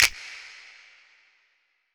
Snap (Teleport 2 Me, Jamie).wav